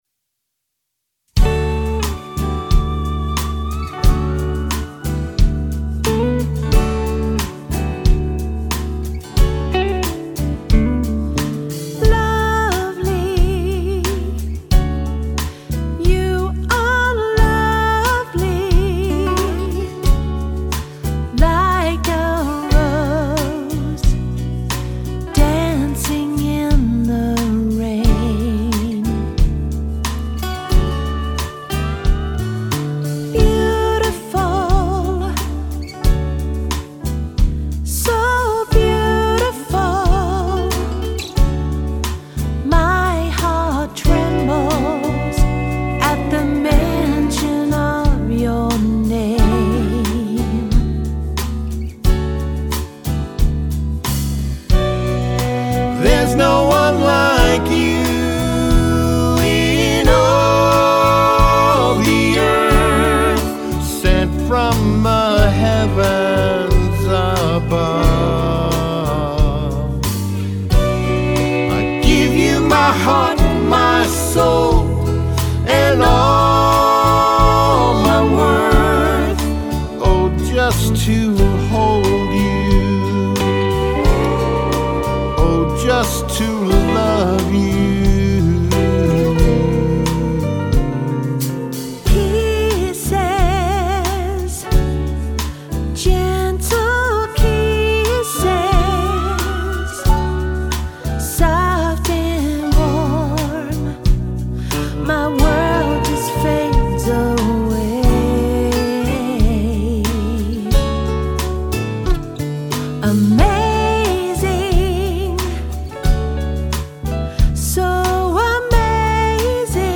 sweet stripped-down ballad
Country duo